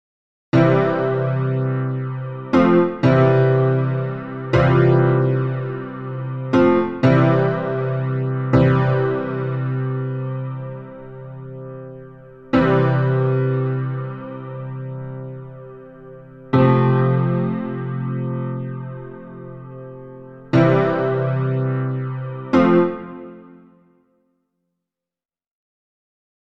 Zunächst ist das Ausganspattern zu hören, danach dann die Variationen, welche mit ChordPotion erzeugt wurden.
Piano:
chordpotion-piano-pur.mp3